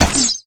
snd_arrow.ogg